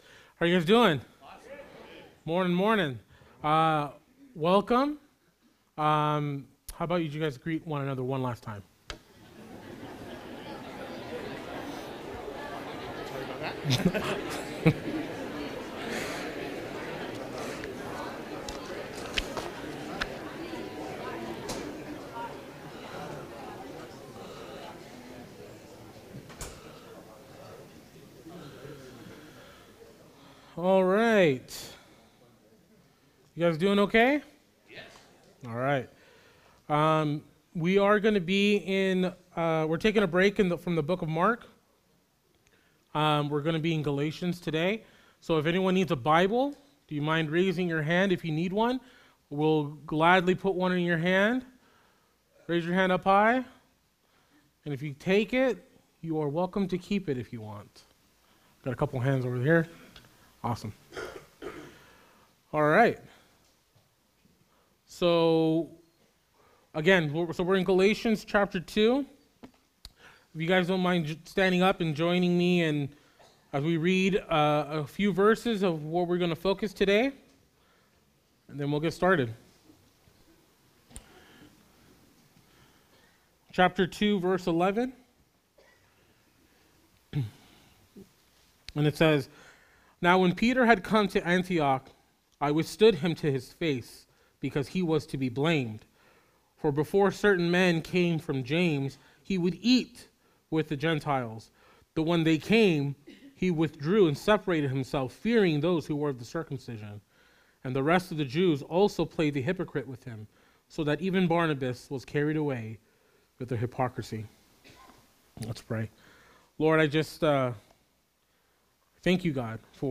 Calvary Chapel Saint George - Sermon Archive
Related Services: Sunday Mornings